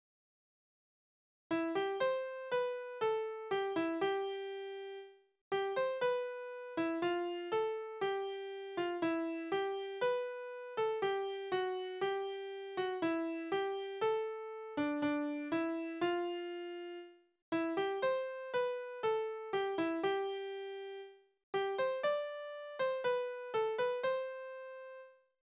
Tonart: C-Dur
Taktart: 4/4
Tonumfang: Oktave
Besetzung: vokal